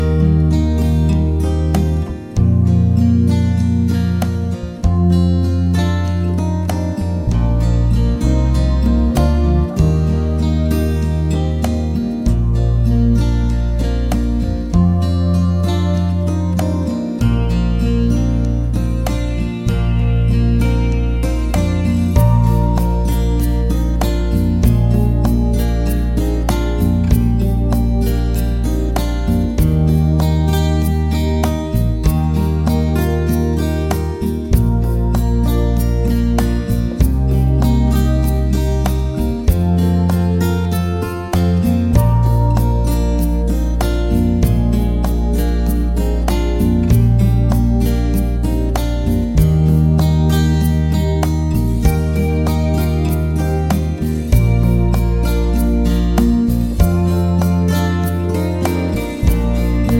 no Backing Vocals Country (Female) 3:58 Buy £1.50